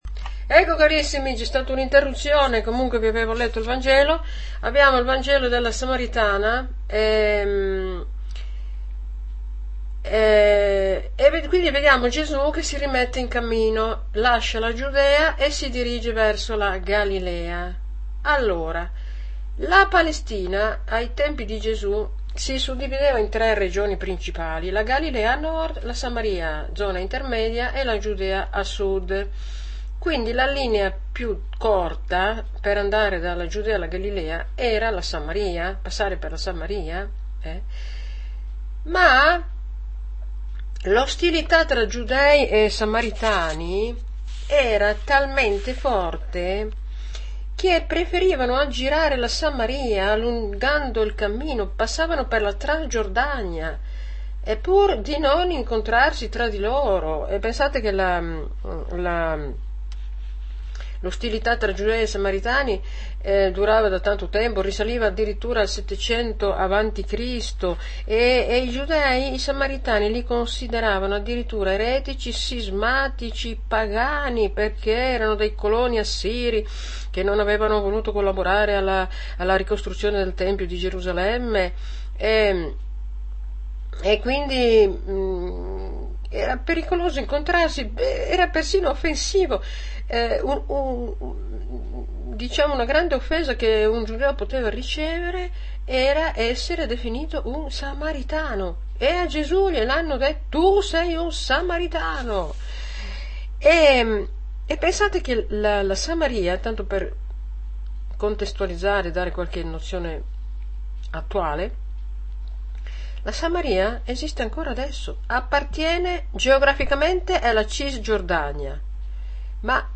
AUDIO Audio commento alla liturgia - Gv 4,5-42